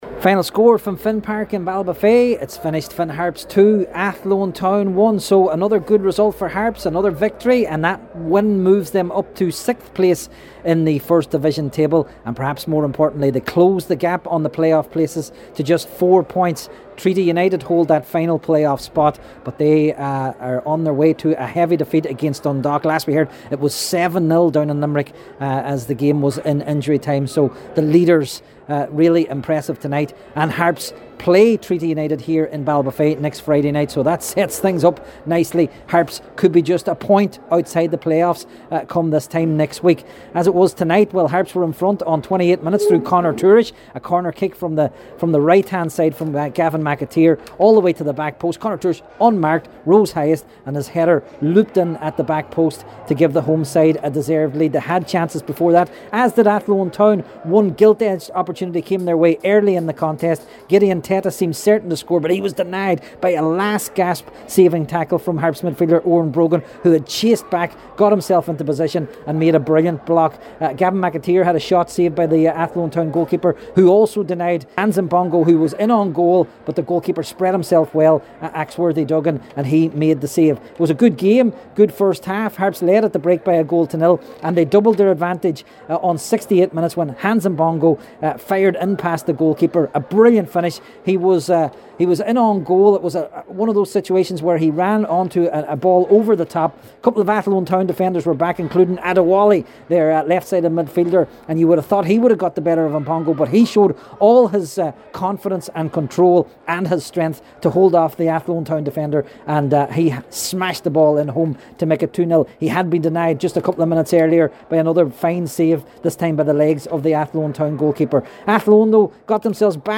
the full time report…